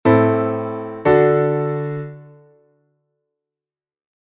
Cadencia Picarda: cambia de modo menor a modo maior no I grao.
LA-DO-MI-LA; RE-RE-FA#-LA